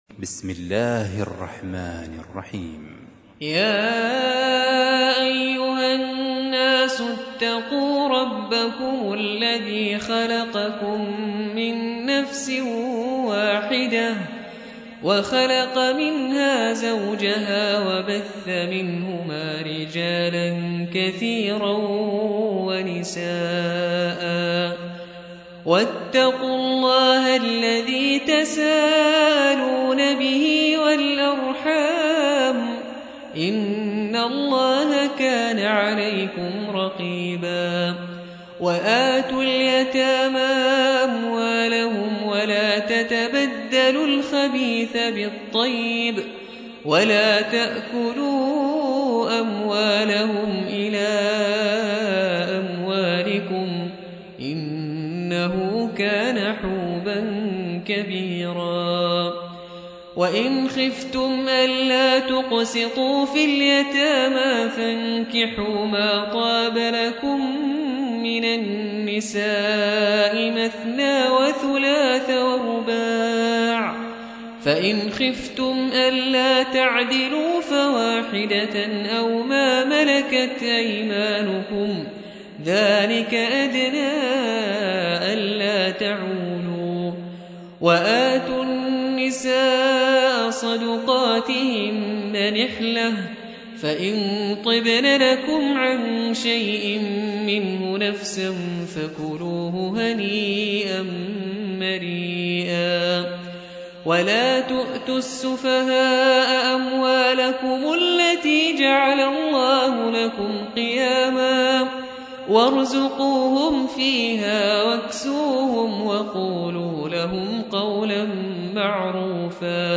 4. سورة النساء / القارئ